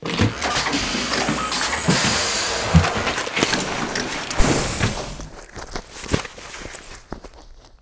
suitremove.wav